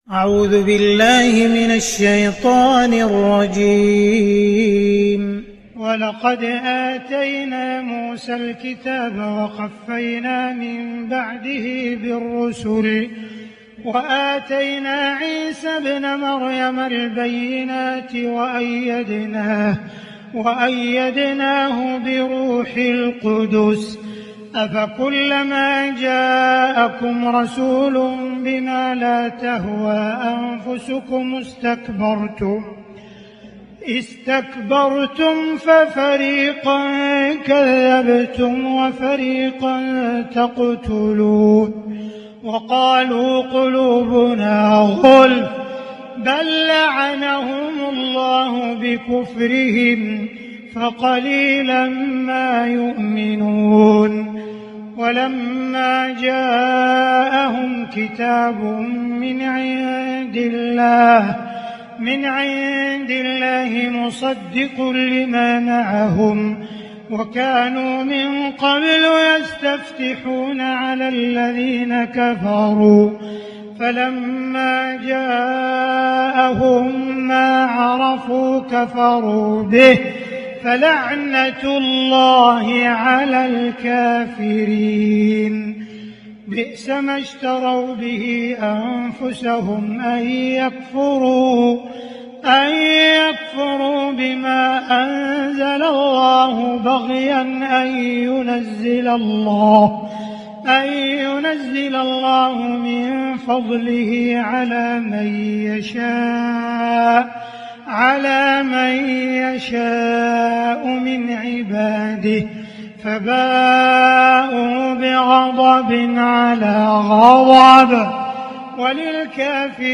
تلاوات التراويح و التهجد كاملة رمضان 1443هـ للشيخ عبدالرحمن السديس > تراويح الحرم المكي عام 1443 🕋 > التراويح - تلاوات الحرمين